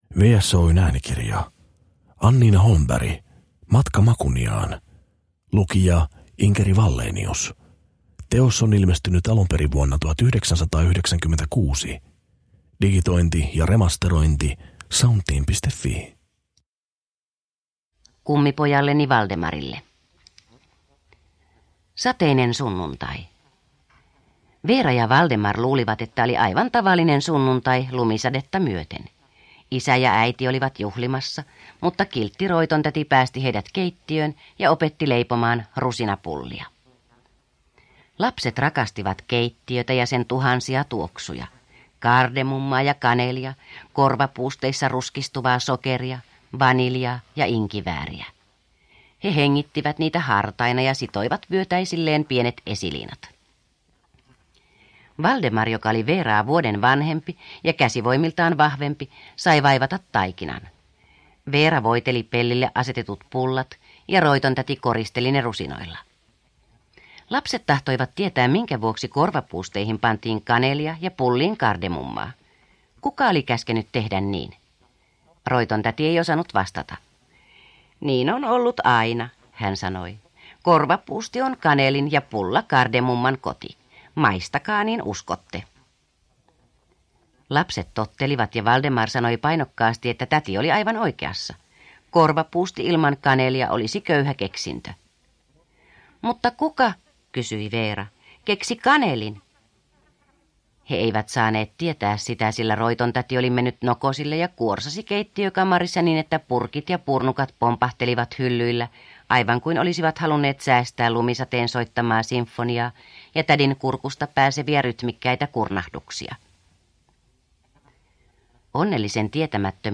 Matka Makuniaan – Ljudbok